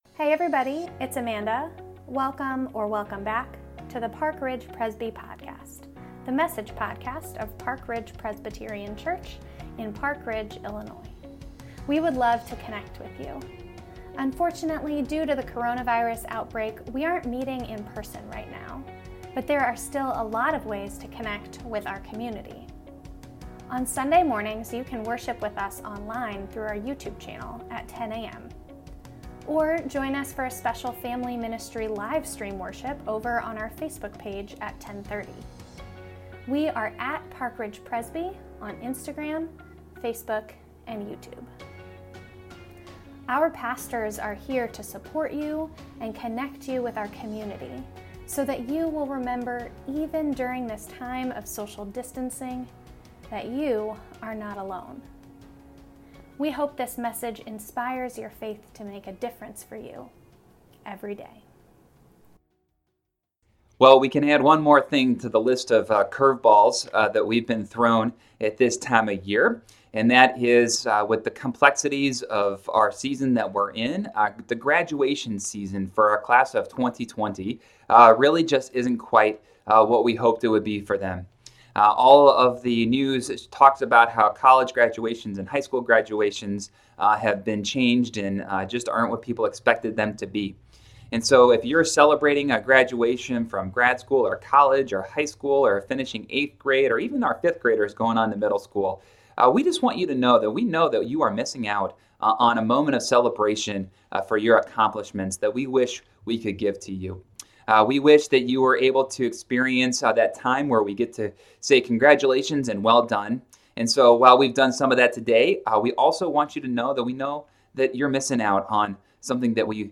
Sunday, May 17, 2020 – Graduation Sunday 2020 – Park Ridge Presbyterian Church